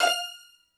STR HIT F5 2.wav